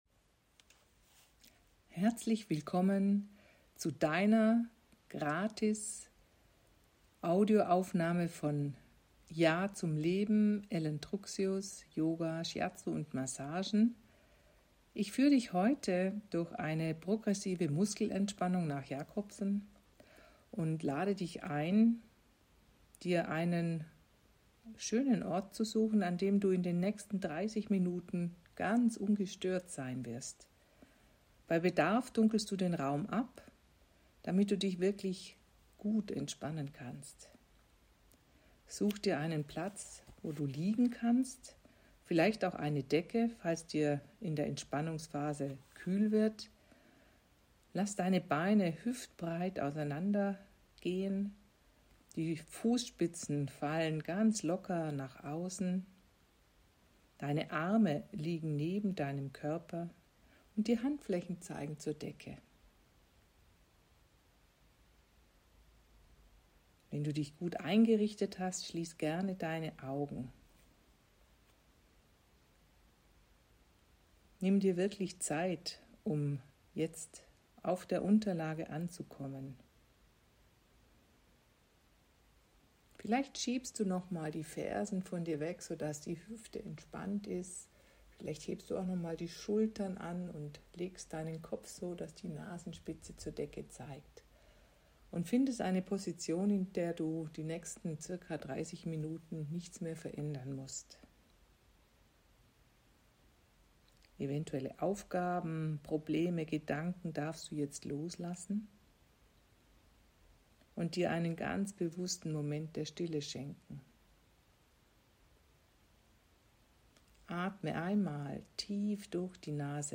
Du sehnst Dich nach Ruhe? Dann klicke Dich unten links zu einer kleinen meditativen Auszeit.